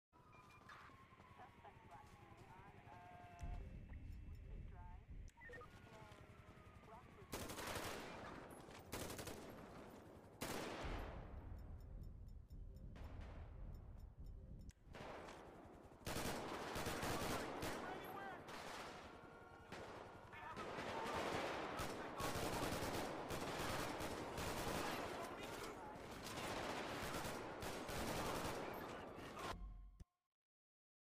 GTA5 Storymode mods - Custom Gun sounds + recoil